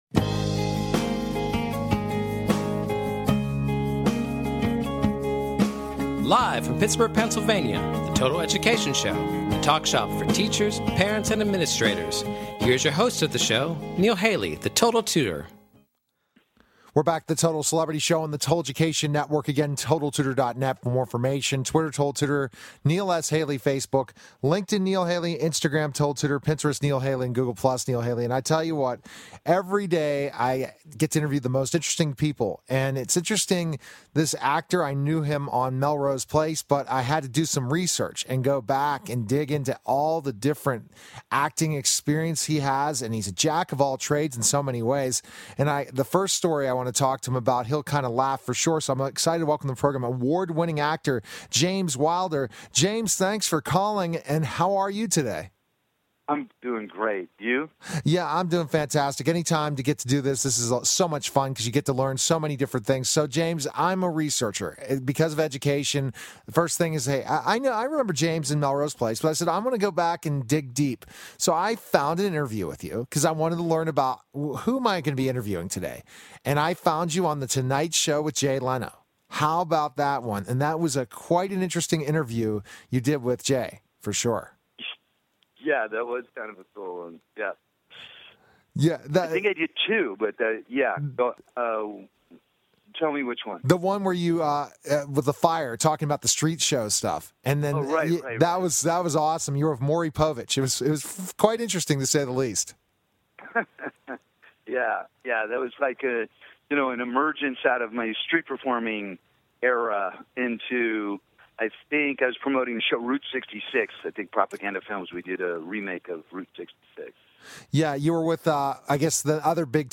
Catch weekly discussions focusing on current education news at a local and national scale.